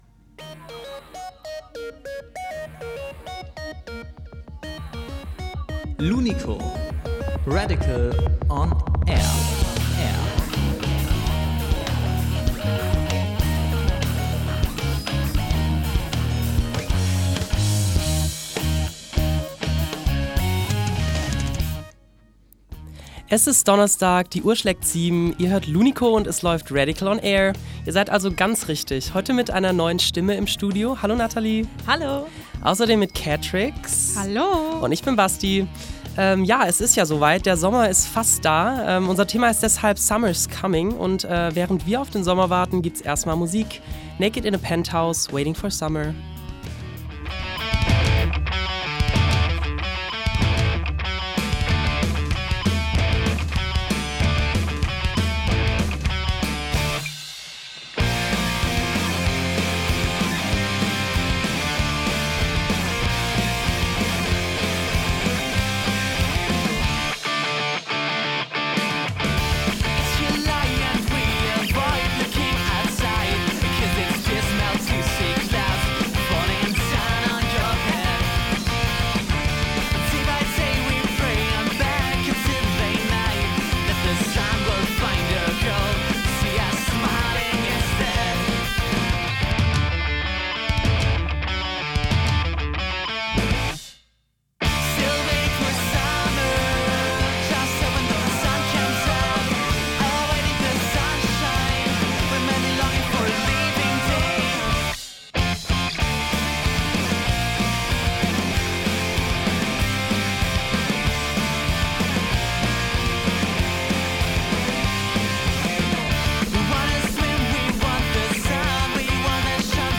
live im Studio DELICIOUS GRANDMA, Sommer-Sendung